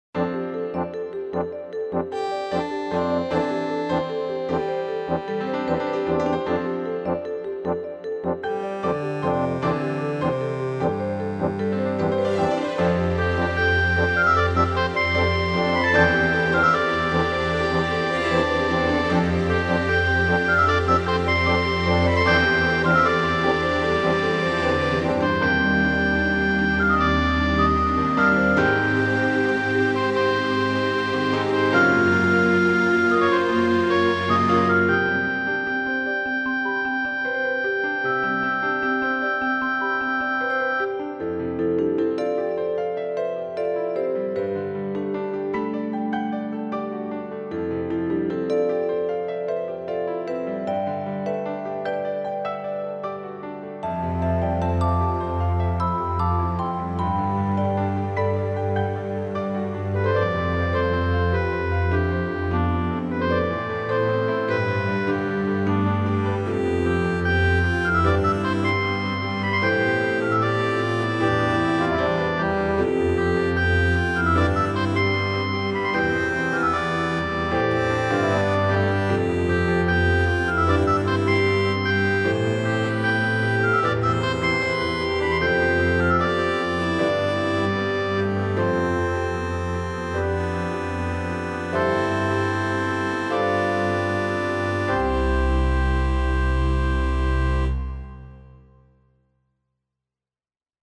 明るく元気な曲。